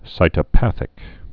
(sītə-păthĭk)